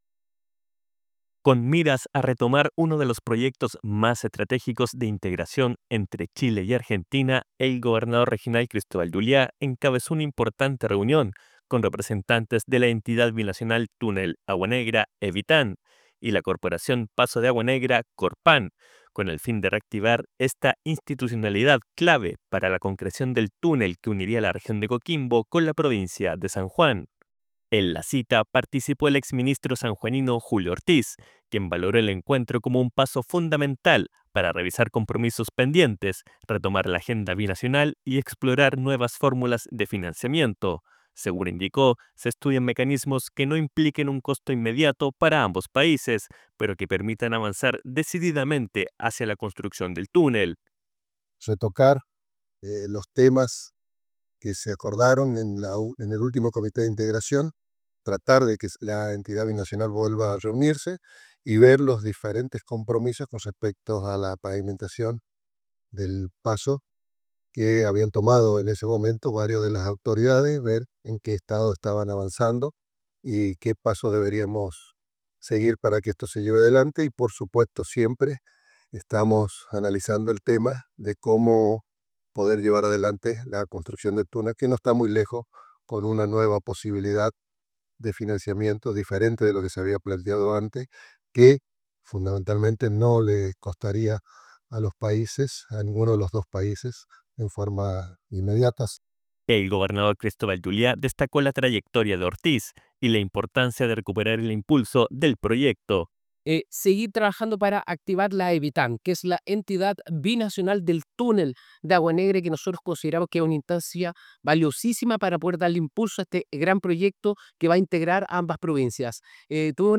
DESPACHO-RADIAL-REUNION-CORPAN-Y-EBITAN.-GORECOQUIMBO-.mp3